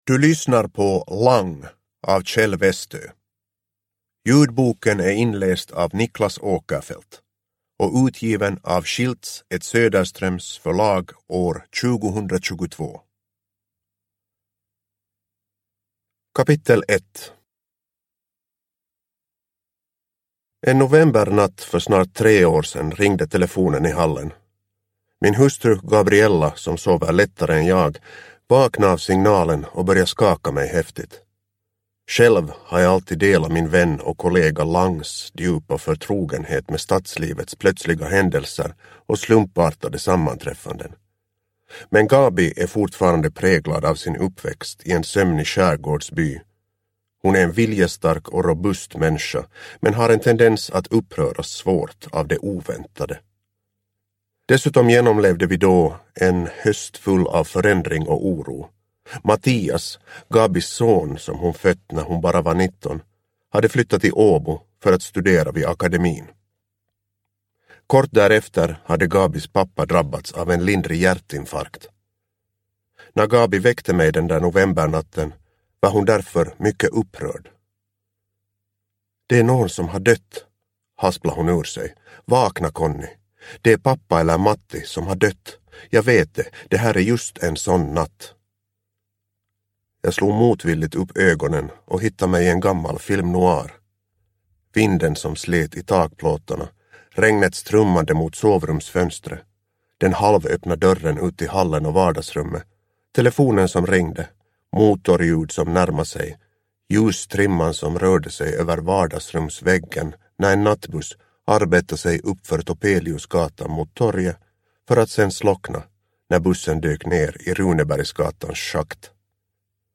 Lang – Ljudbok – Laddas ner